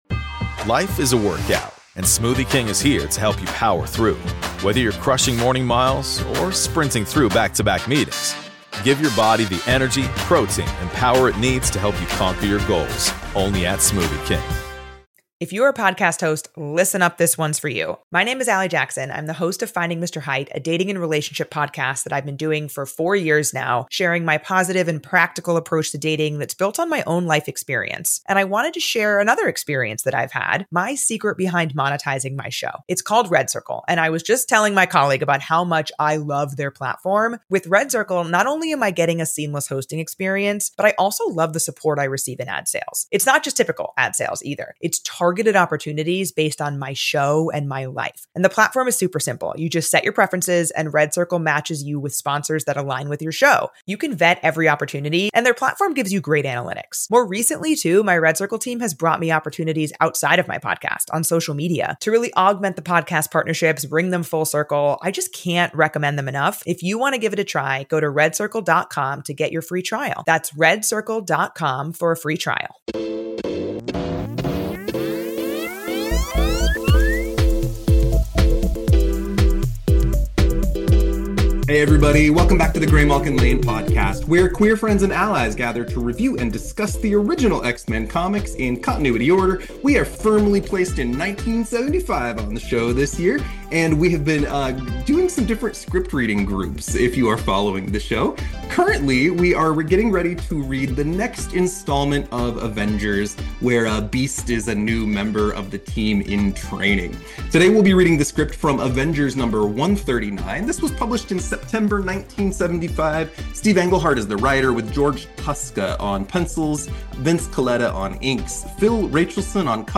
And then a script reading of X-Men Unlimited #6!